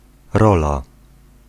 Ääntäminen
Synonyymit party faction position shed role element (brittienglanti) parting component chelek portion section function depart installment Ääntäminen US : IPA : /pɑɹt/ UK : IPA : /pɑːt/ AU : IPA : /pɑːt/ Lyhenteet ja supistumat (laki) pt. pt